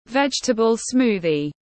Sinh tố rau xanh tiếng anh gọi là vegetable smoothie, phiên âm tiếng anh đọc là /ˈvedʒ.tə.bəl ˈsmuː.ði/